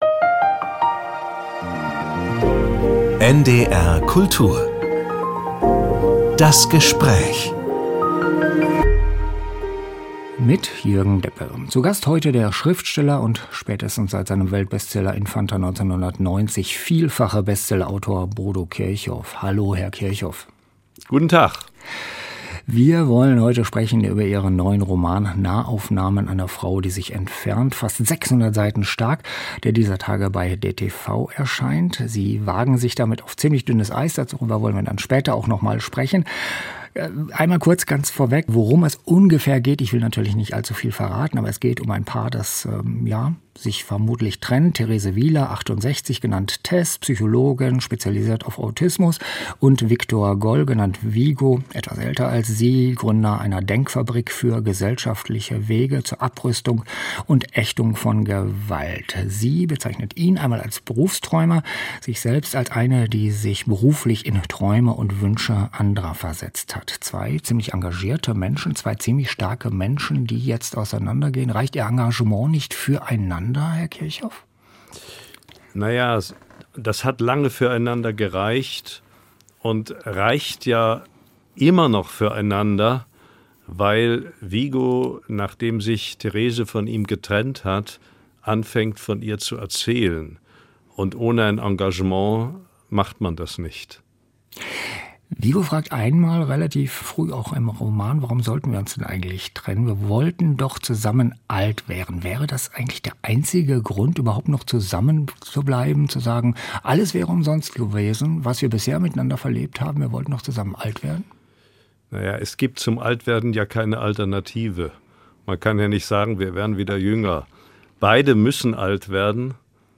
"Nahaufnahmen einer Frau" - Gespräch mit Bodo Kirchhoff ~ NDR Kultur - Das Gespräch Podcast